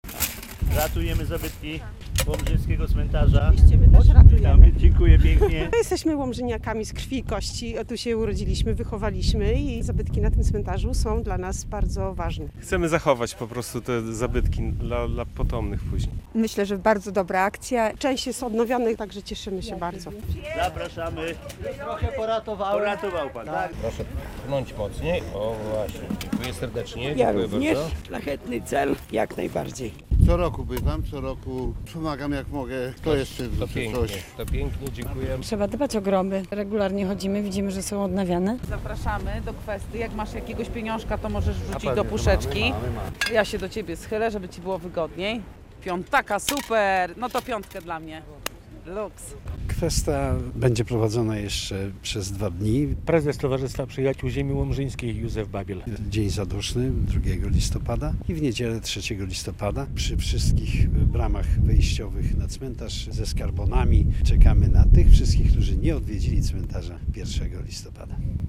Kwesta w Łomży - relacja